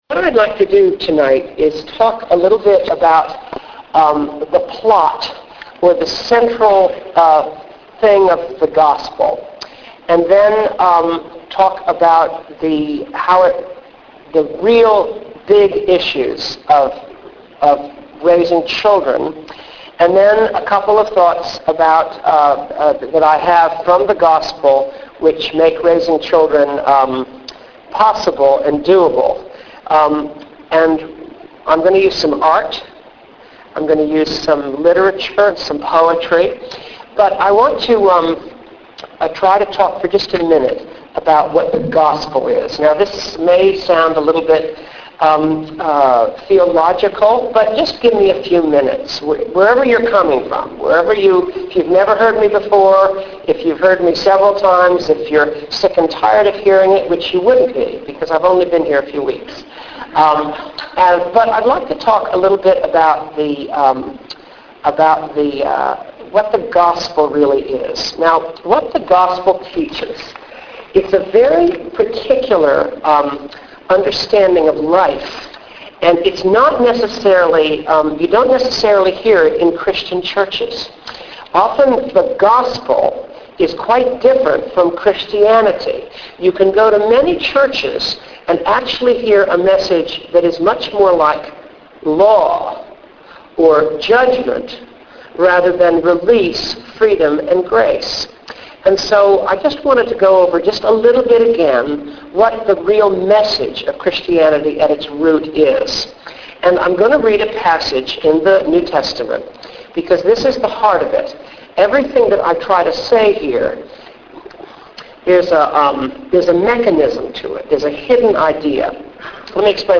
Venue: All Saints Church Chevy Chase